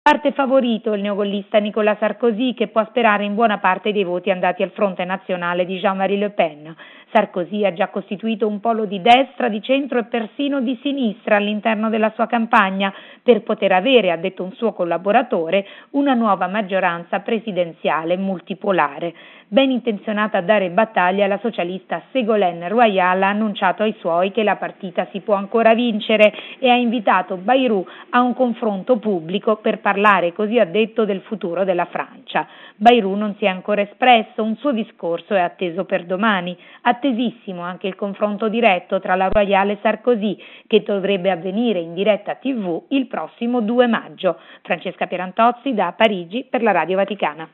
Sentiamo, da Parigi,